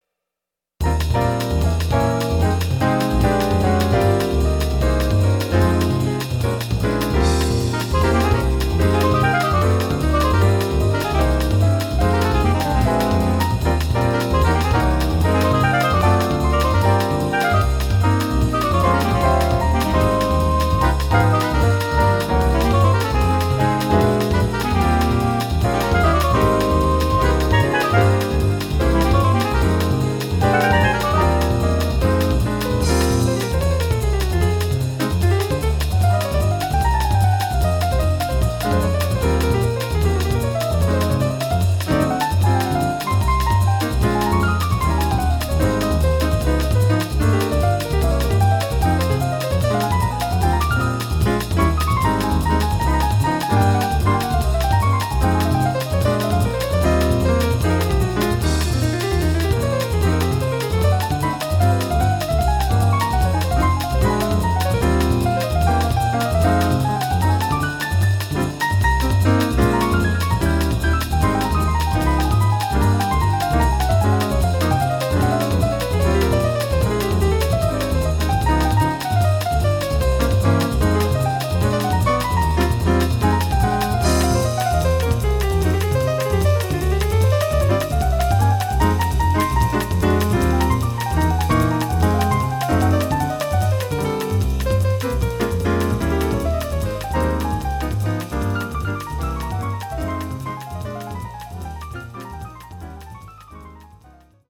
Here are demo recordings I did at home of ten original pieces for jazz quintet. These are all short clips (1-2 minutes); the head with a chorus or two of piano solo.